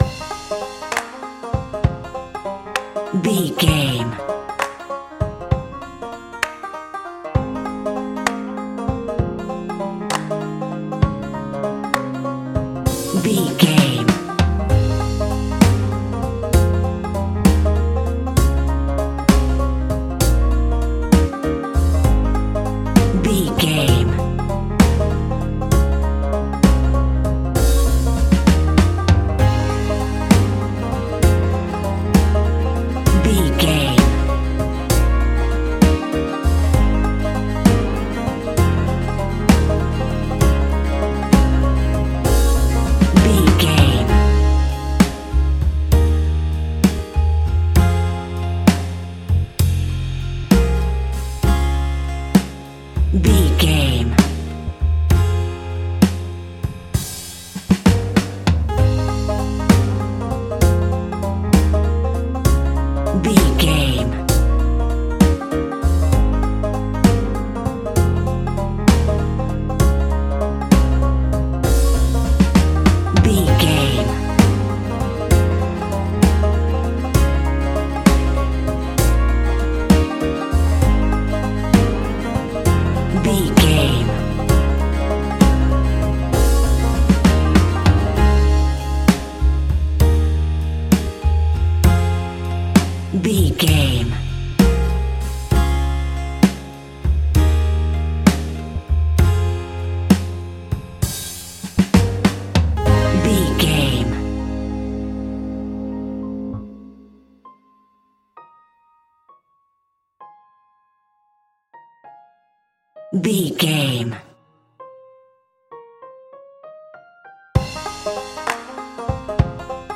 Modern Country Nashville Story.
Ionian/Major
A♭
pop rock
indie pop
fun
energetic
uplifting
instrumentals
upbeat
groovy
guitars
bass
drums
piano
organ